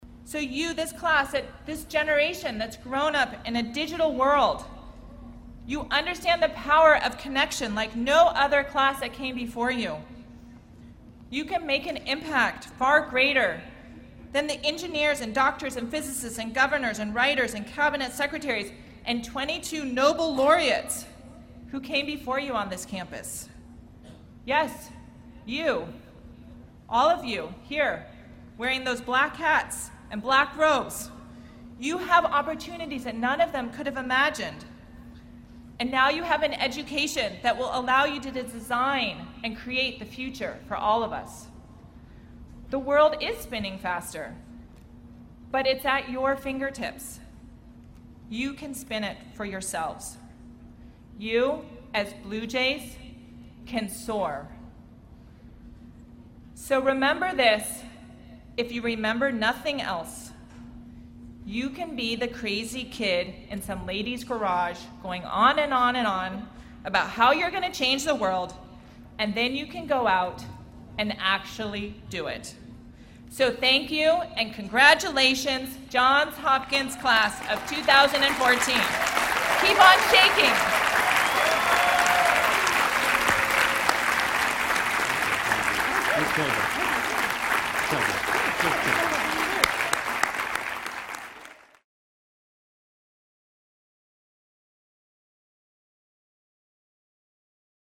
公众人物毕业演讲 第202期:苏珊沃西基2014在约翰霍普金斯大学(15) 听力文件下载—在线英语听力室